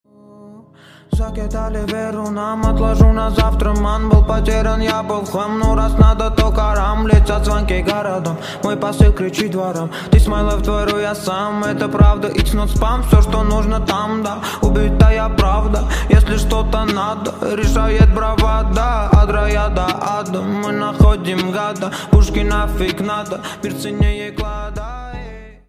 • Качество: 128, Stereo
поп
мужской голос
спокойные
Rap